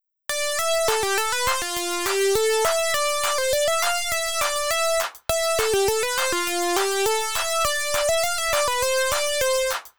なんとこの通り、全部白鍵で構成されています！
先ほどとまるで別の鍵盤を使っているのに、全然違和感がないでしょう？
これは同じ間隔の取り方を維持したまま、基準の音をずらしていったためです。